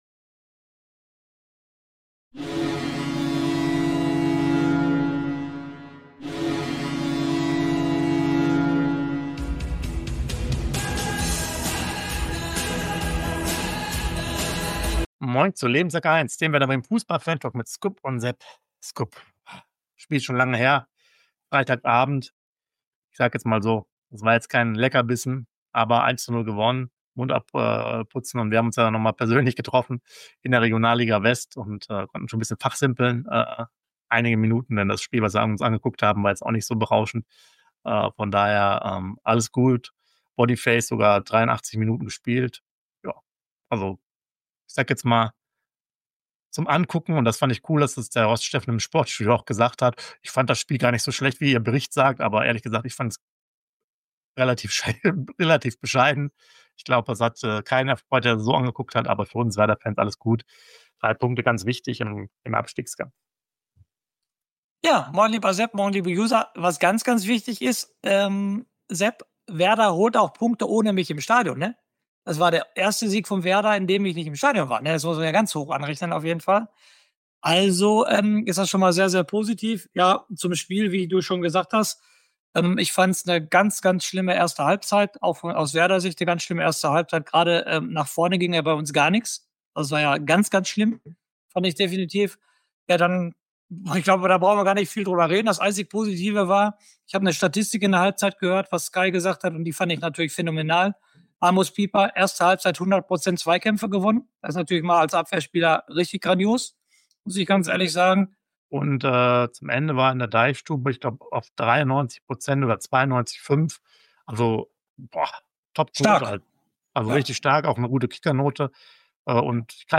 WERDER BREMEN FUßBALL FANTALK!!! Hier gibt es Infos, News und heiße Diskussionen vor, zwischen und nach den Spieltagen zu unserem Verein.